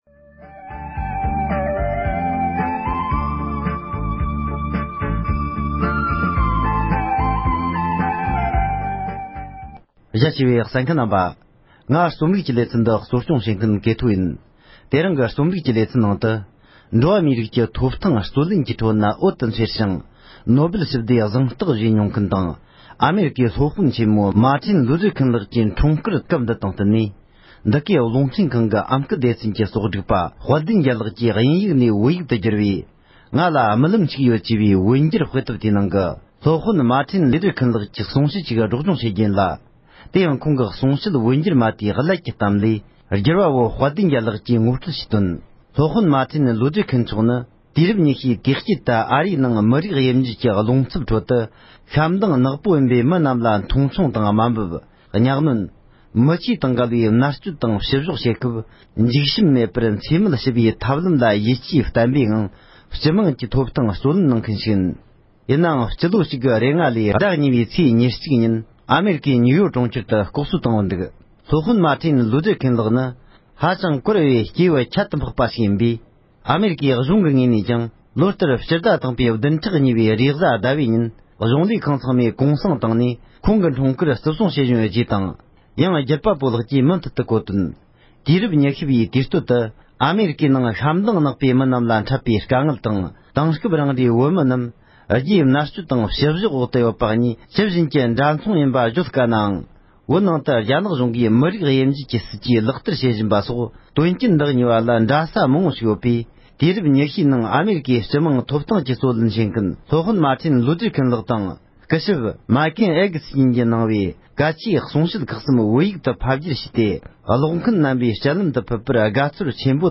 ༄༅༎དེ་རིང་གི་དཔེ་དེབ་ཀློག་འདོན་གྱི་ལེ་ཚན་ནང་དུ། འགྲོ་བ་མིའི་ཐོབ་ཐང་རྩོད་ལེན་པ་གྲགས་ཅན་སློབ་དཔོན་མ་ཐེན་ལོའུ་ཏར་ཁེན་ལགས་ཀྱི་གསུང་བཤད་བོད་སྐད་དུ་བསྒྱུར་བ་ཞིག་གི་མཚམས་སྦྱོར་དང་ཀློག་འདོན་ཞུས་པར་གསན་རོགས་གནོངས༎